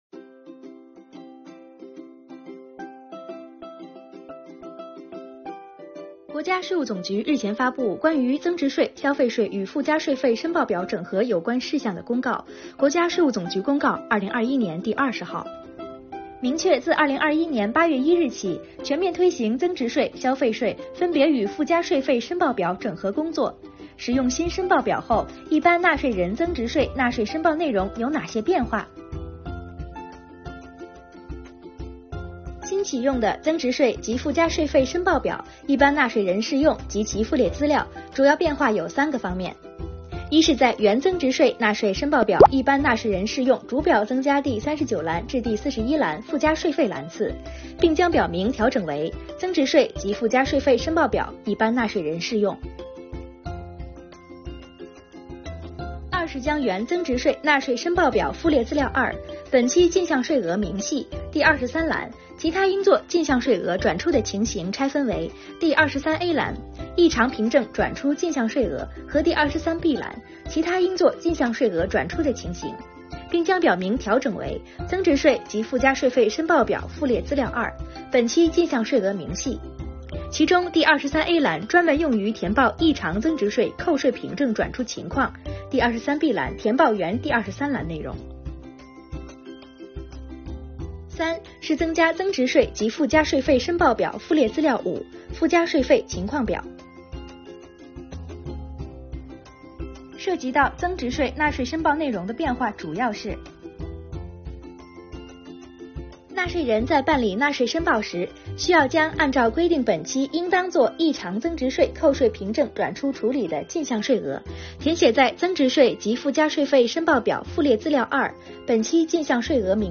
动画制作、配音